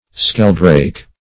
Search Result for " skeldrake" : The Collaborative International Dictionary of English v.0.48: Skeldrake \Skel"drake`\, or Skieldrake \Skiel"drake`\, n. (Zool.) (a) The common European sheldrake.